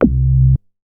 MoogPuls 008.WAV